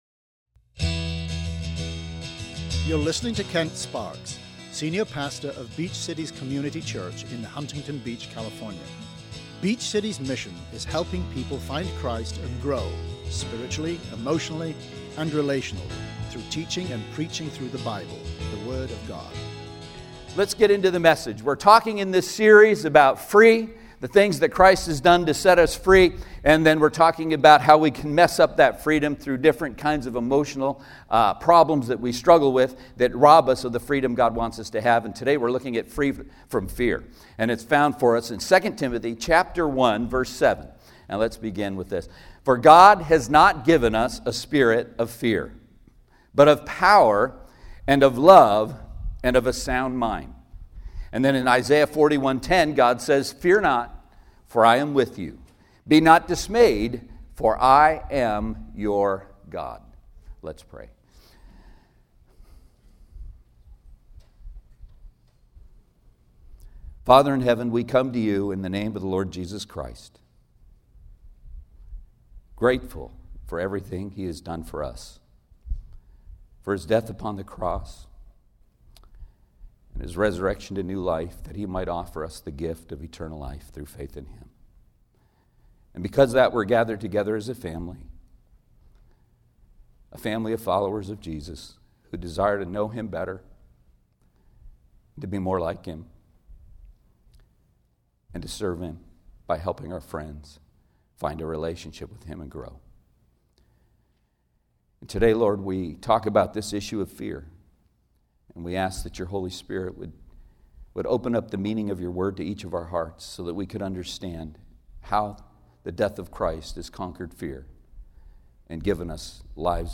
SERMON AUDIO: SERMON NOTES: